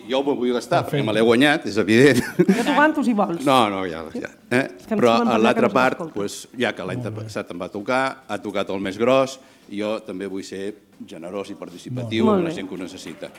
Es van donar a conèixer ahir en el sorteig celebrat a l’Auditori Josep-Maria Terricabras, que es va poder seguir en directe per Ràdio Calella TV.